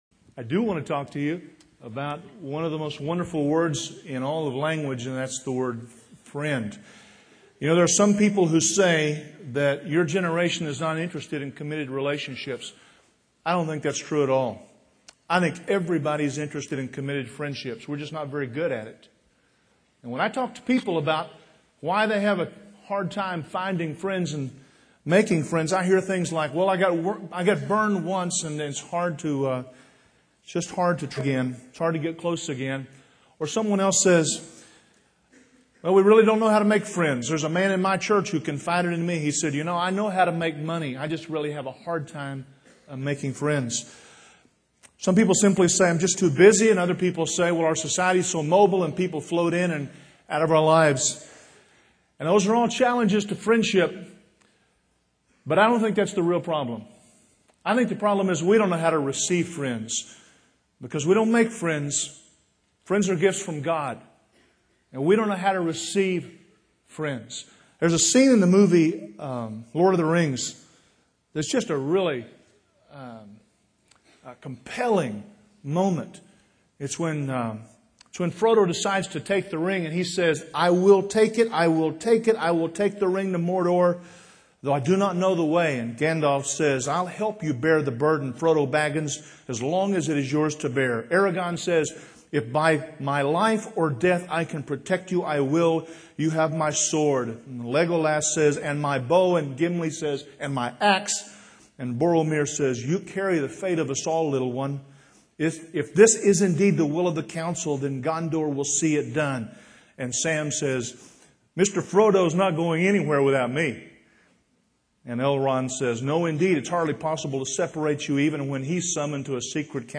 Chapel Service